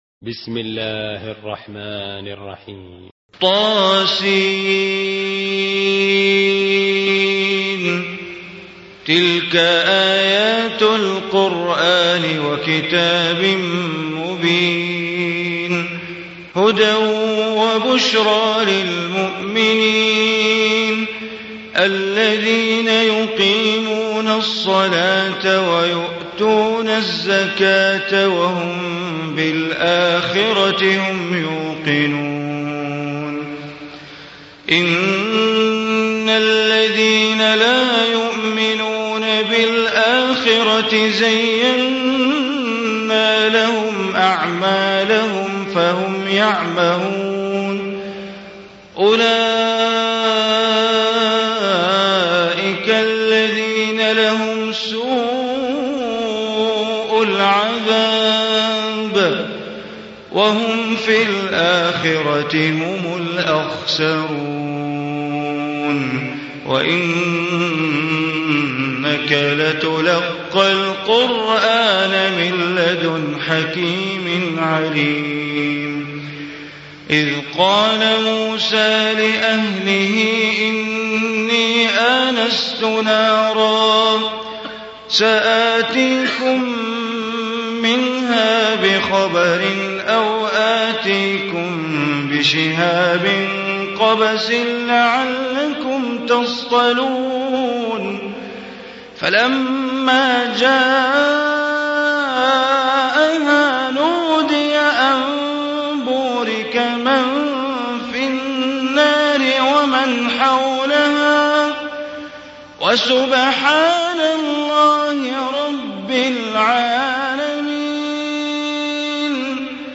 Surah Naml Recitation by Sheikh Bandar Baleela
Surah Naml, listen online mp3 tilawat / recitation in Arabic recited by Imam e Kaaba Sheikh Bandar Baleela.